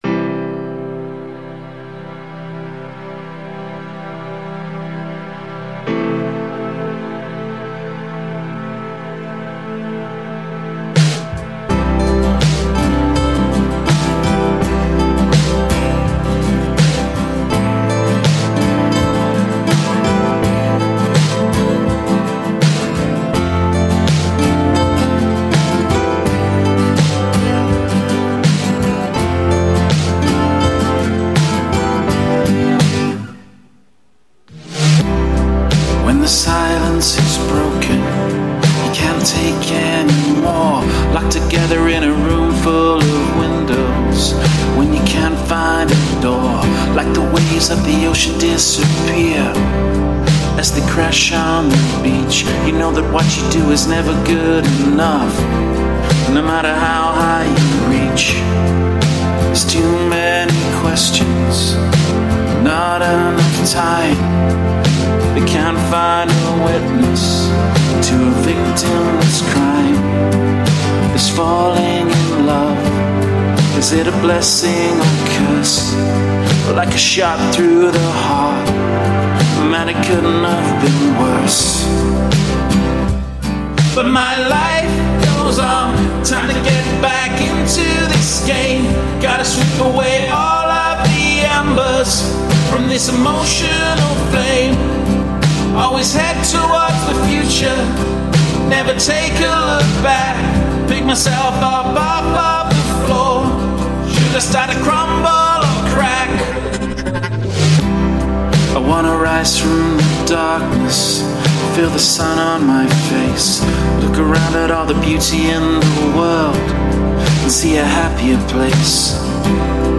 • Super piano intro.
• Peaceful piano opening.
Beautiful entrance by lead and rhythm guitars.
This would be a solid pop ballad.
The deep calm voiced vocalist has a great voice.